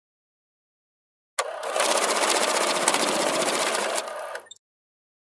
Money Counter Sound Effect Free Download
Money Counter